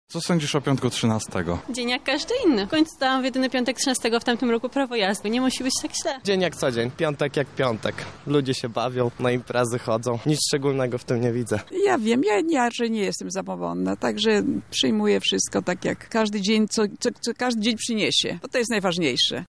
sonda piątek 13